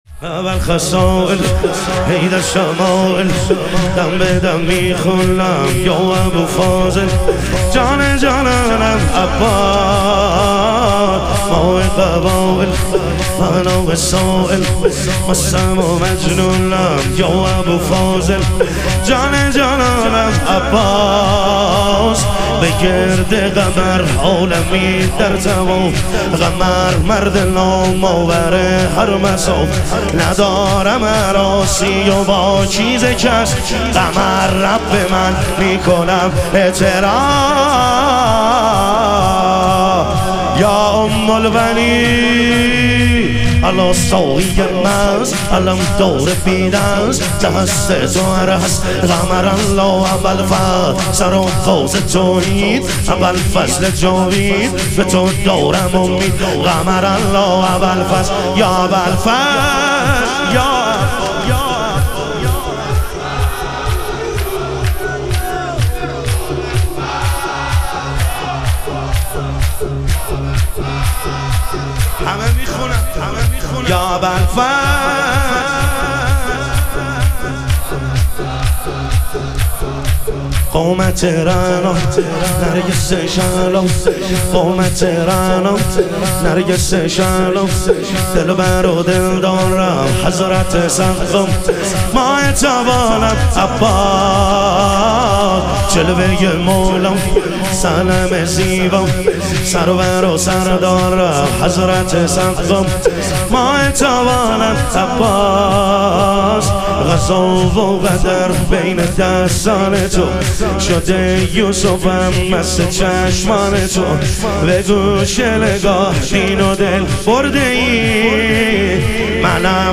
شهادت حضرت ام البنین علیها سلام - شور